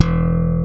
ALEM SLAP E1.wav